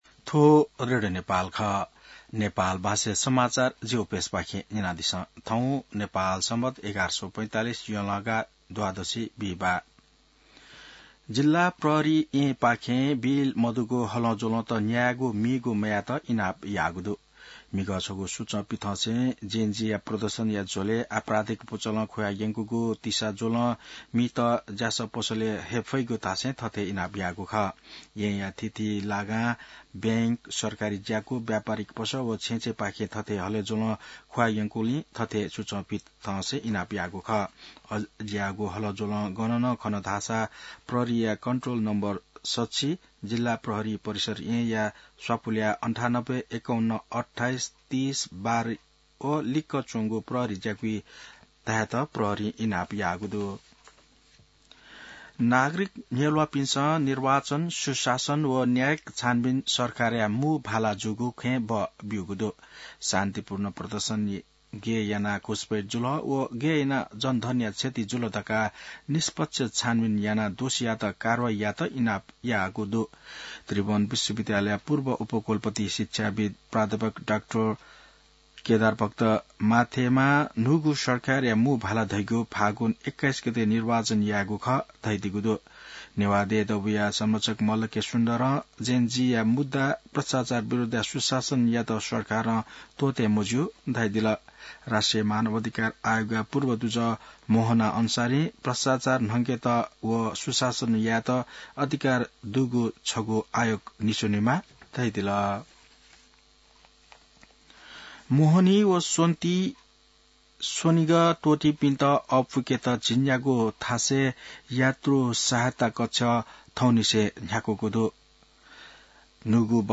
नेपाल भाषामा समाचार : २ असोज , २०८२